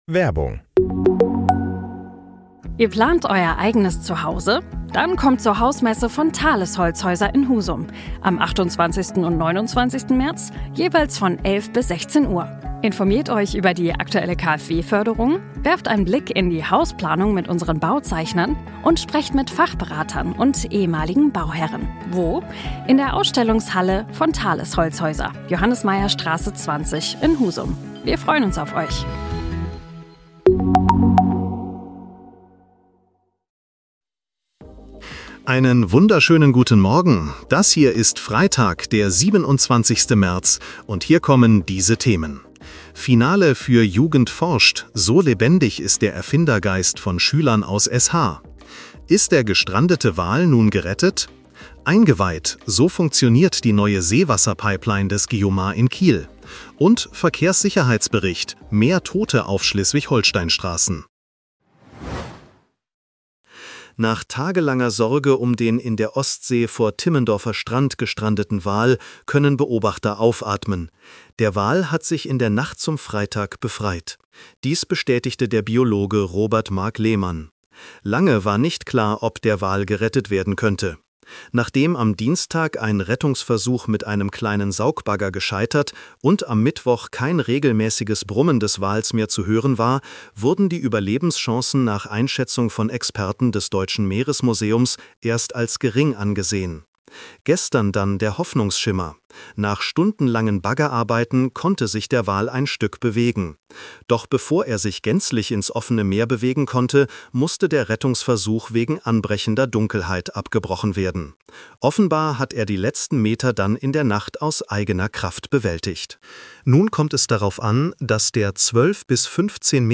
Nachrichten-Podcast bekommst Du ab 7:30 Uhr die wichtigsten